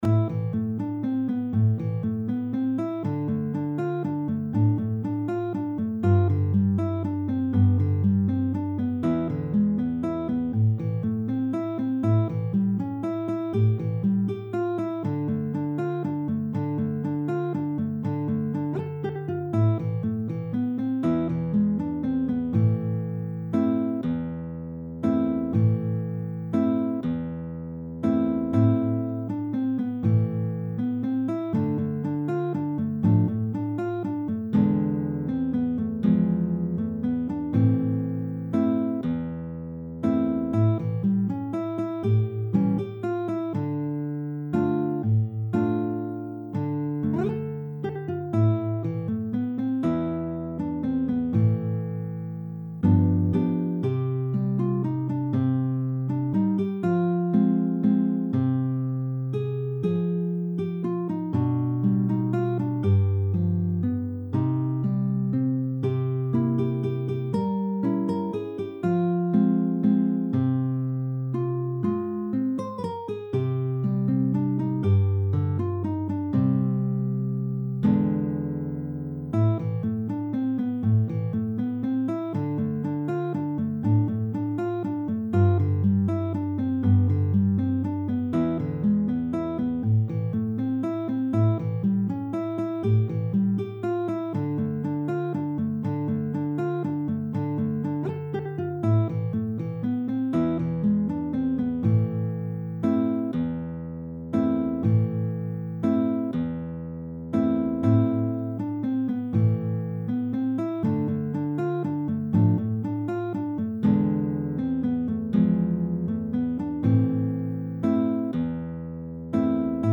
per sola chitarra
classica o acustica